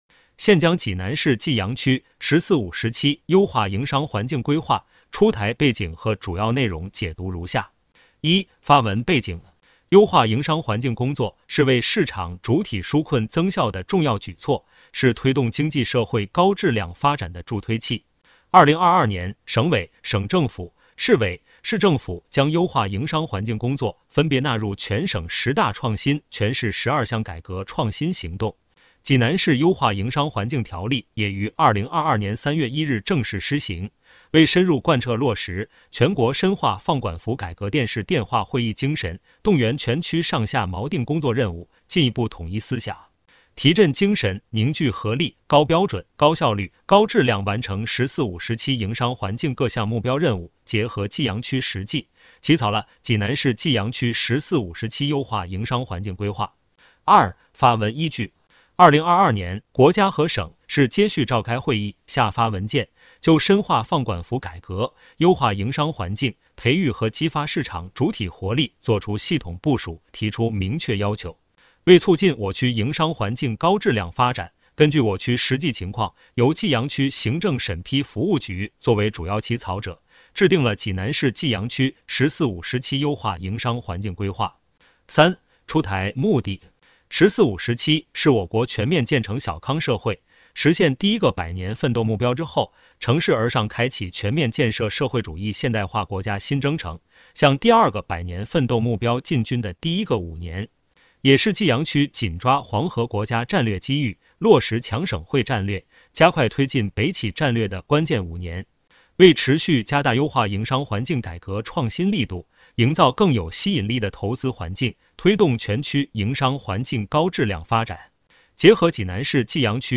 有声朗读《济南市济阳区“十四五”时期优化营商环境规划》音频解读.mp3